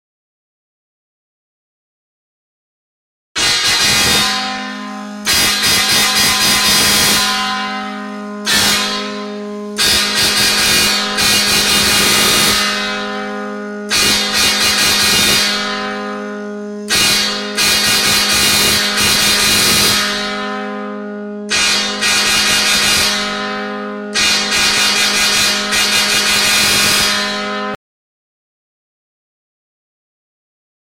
HD450C Six Tube Ultra Resonant Air Dadan Horn